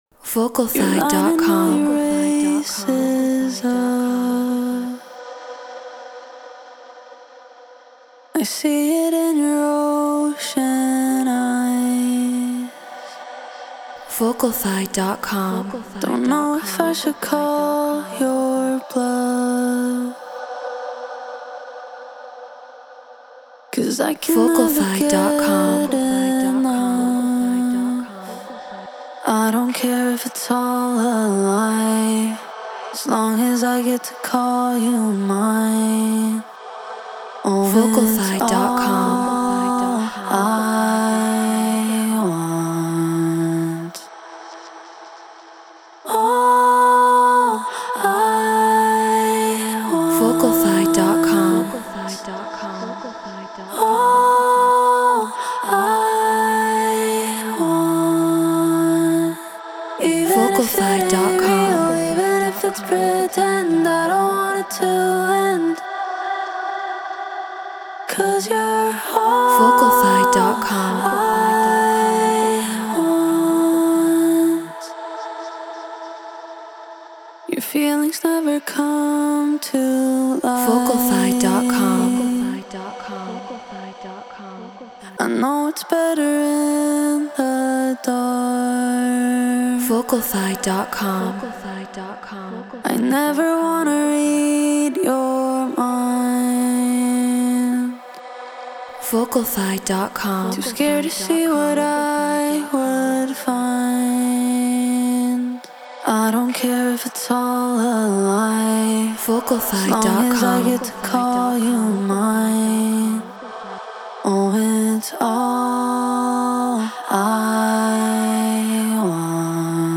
Non-Exclusive Vocal.
EDM 125 BPM Emin